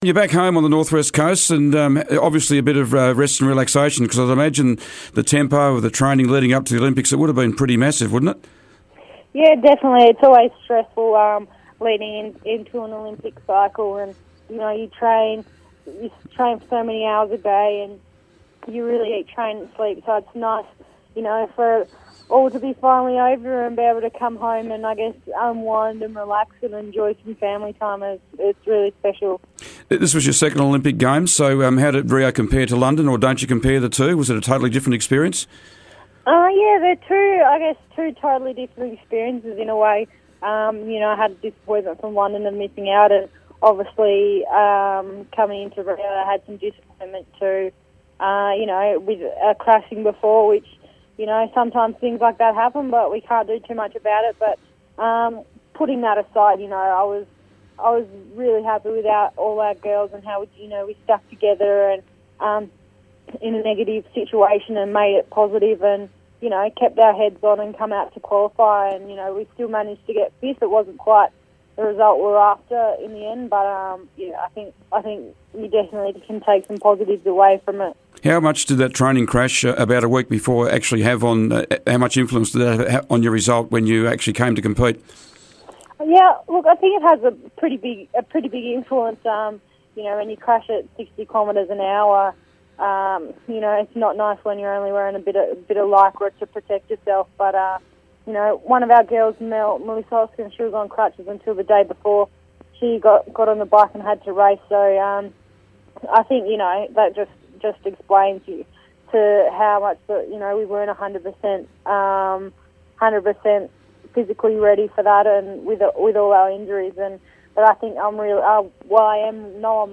caught up with Amy for a chat this morning on Saturday Sport . . .